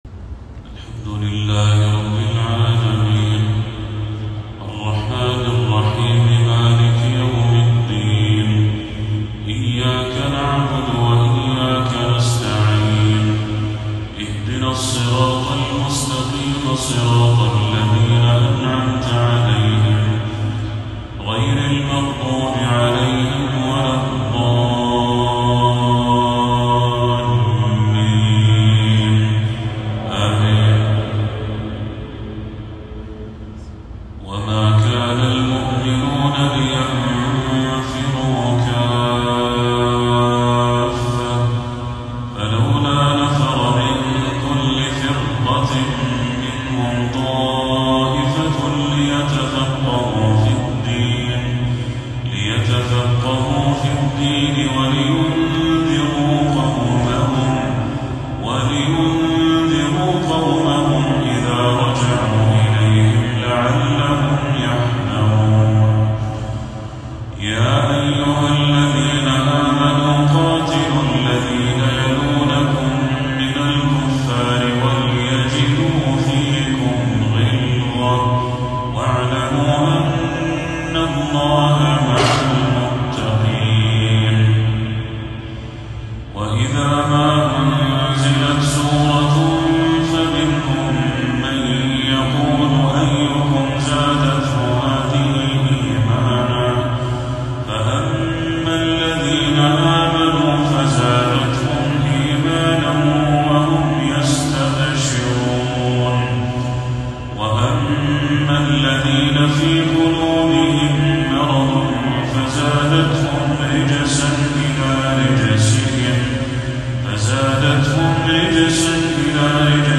إبداع أمتع الأسماع! | تلاوة لخواتيم سورة التوبة وما تيسّر من يونس